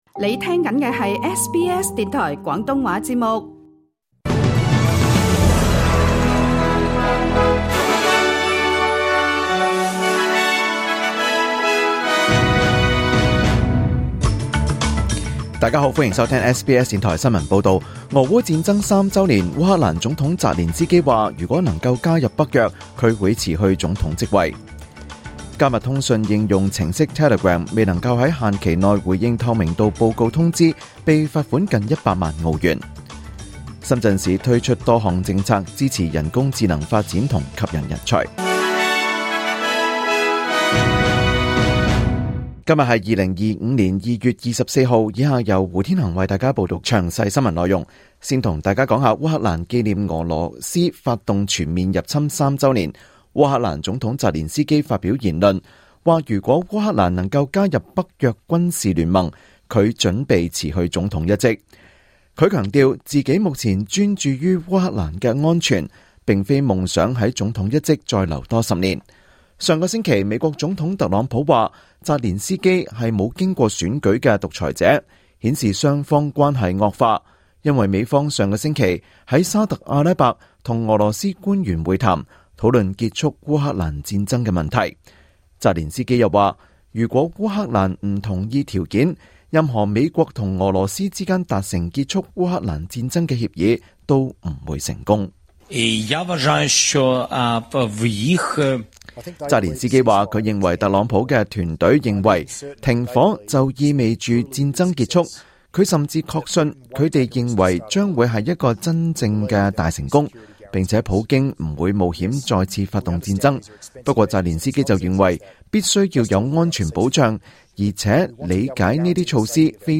2025 年 2 月 24 日 SBS 廣東話節目詳盡早晨新聞報道。